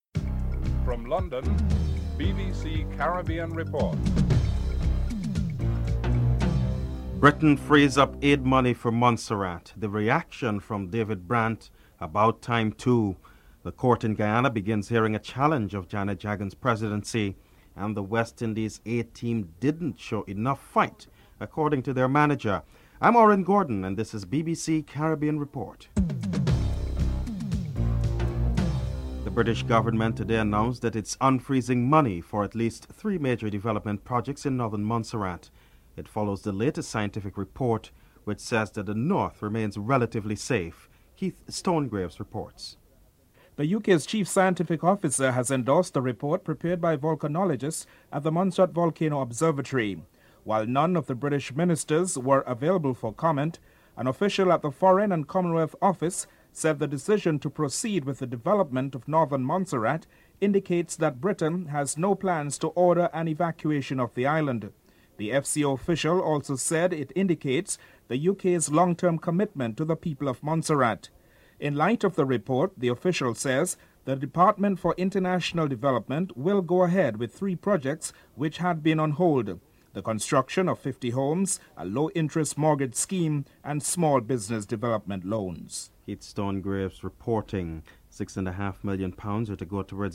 West Indies A Team Manager, Roger Harper and Football Coach Rene Simoes are interviewed (11:04-15:28)